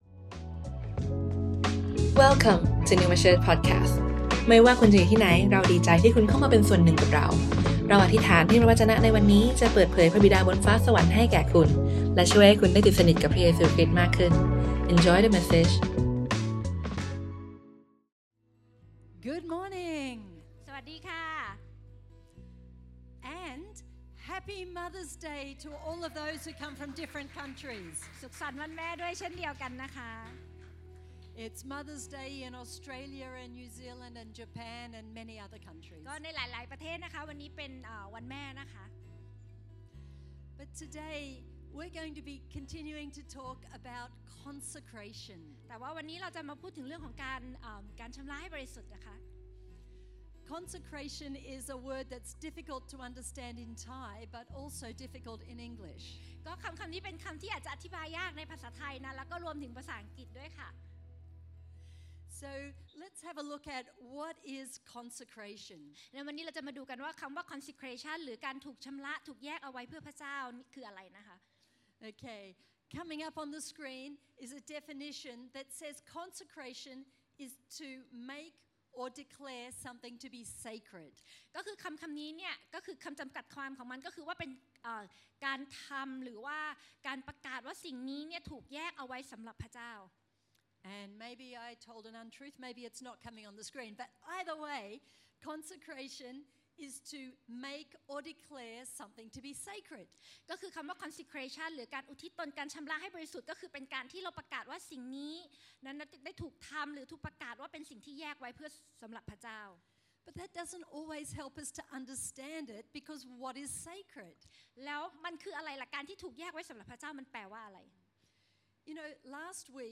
Originally recorded on Sunday 12nd May 2024, at Neuma Bangkok.